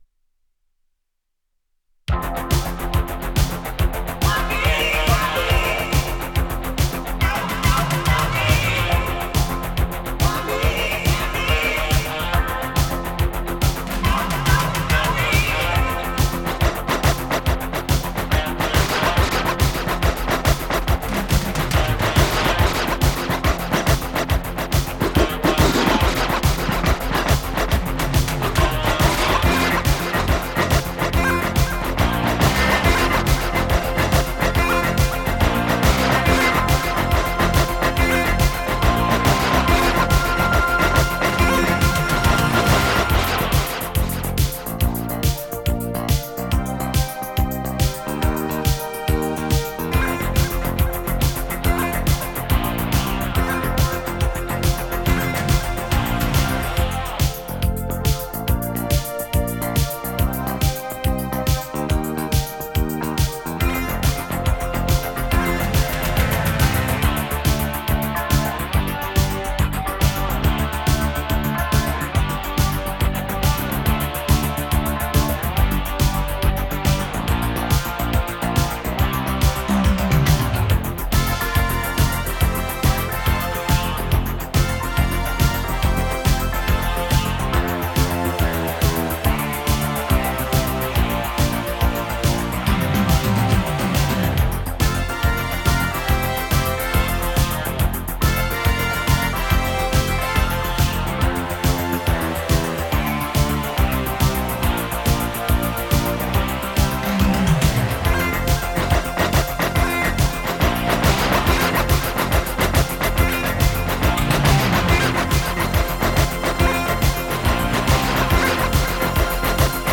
类型：迪斯科